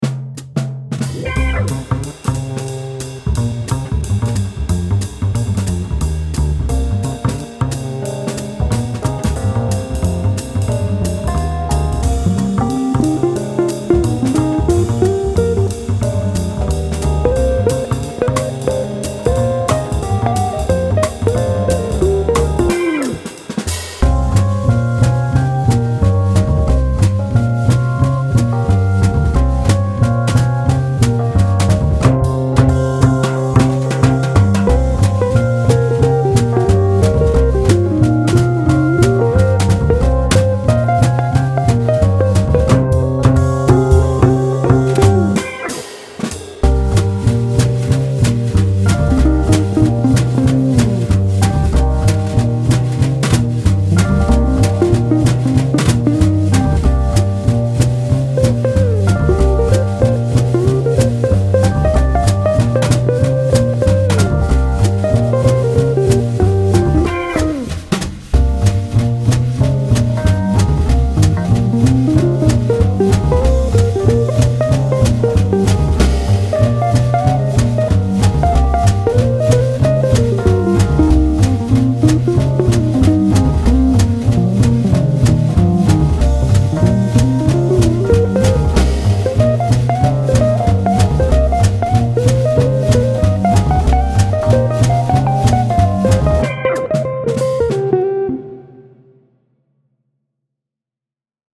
爵士架子鼓采样包
Brush Kit WAV Project 分轨文件